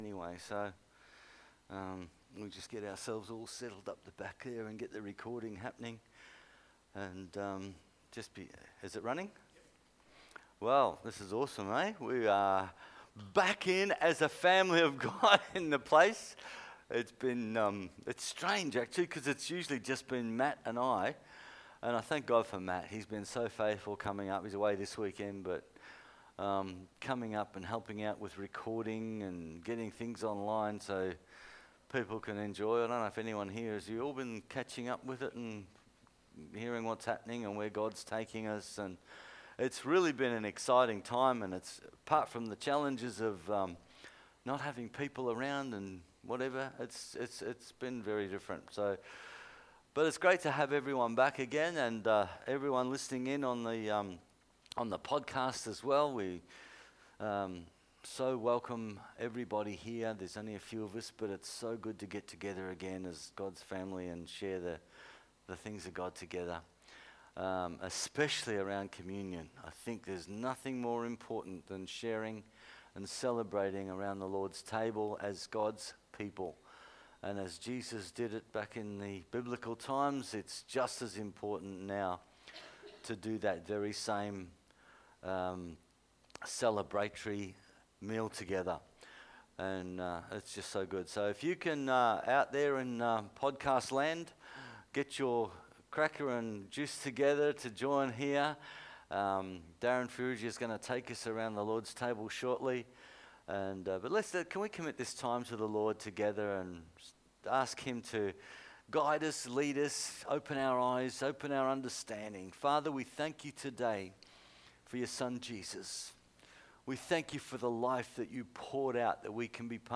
Sunday Service – June 7th 2020